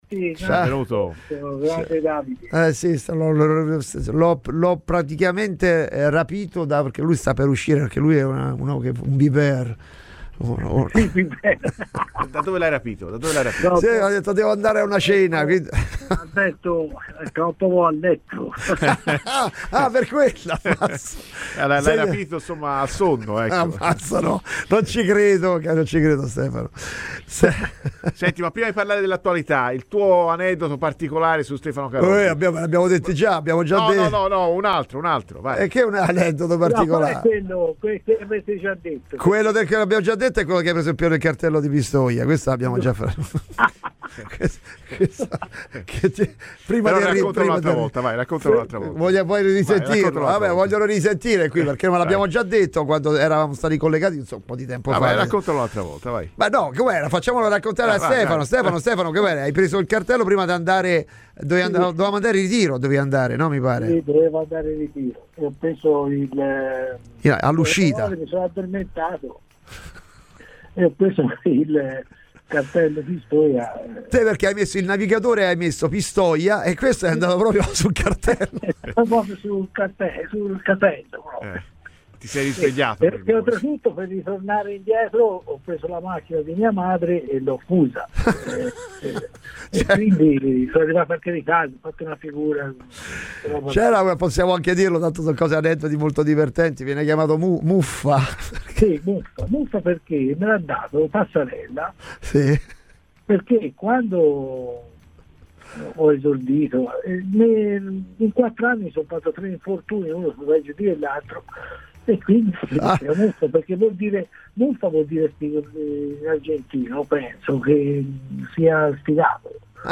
ha parlato ai microfoni di Radio FirenzeViola per commentare il momento della Fiorentina